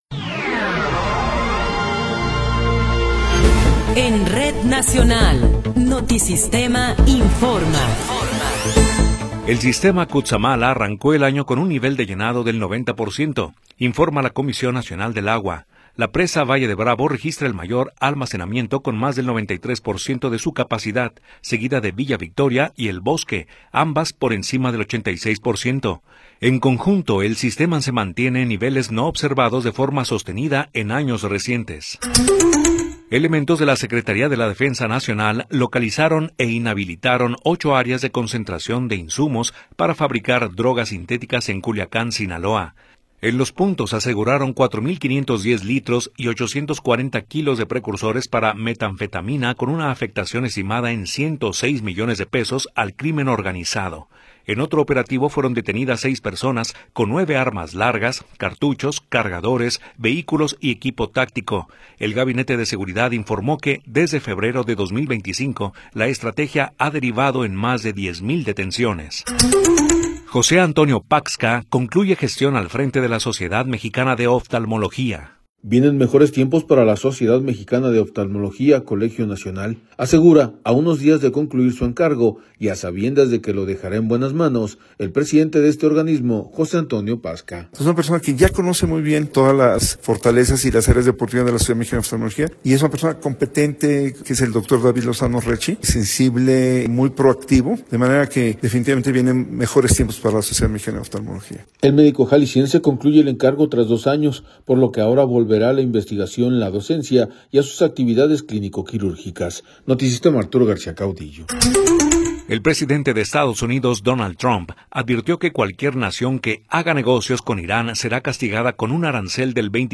Noticiero 18 hrs. – 17 de Enero de 2026